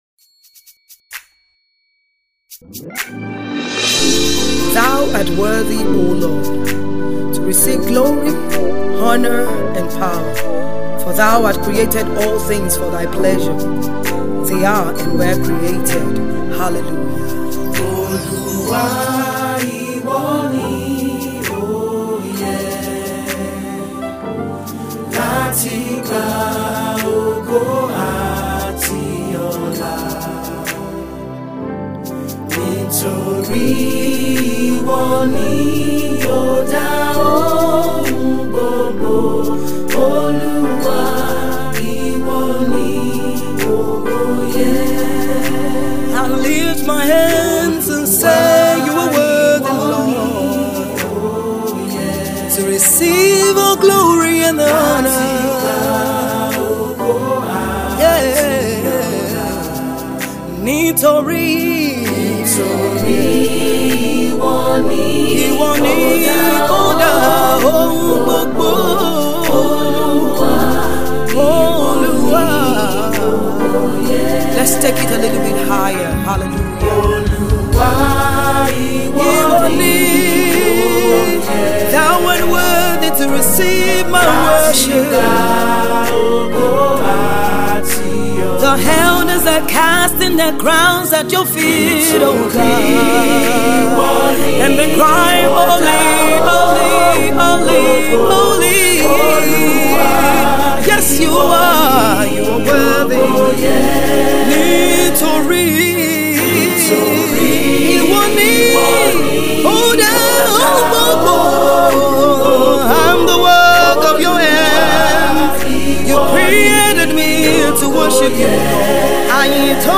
soul lifting sound